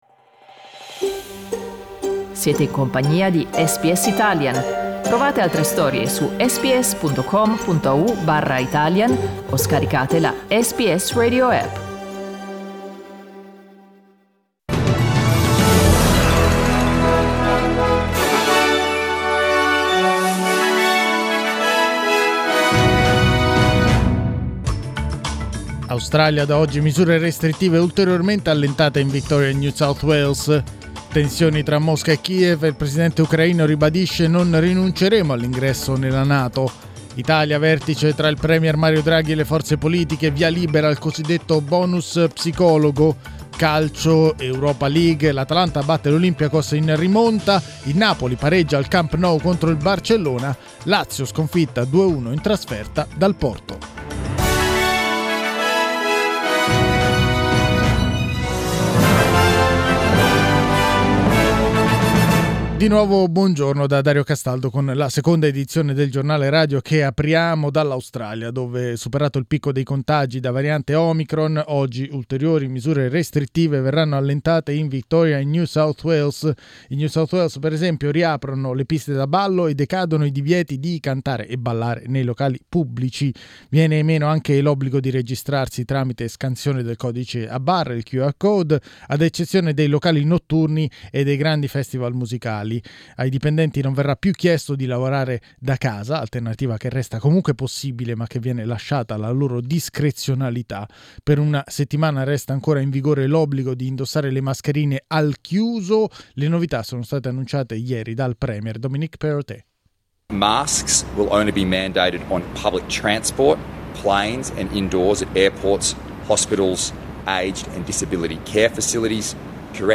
Giornale radio venerdì 18 febbraio 2022
Il notiziario di SBS in italiano.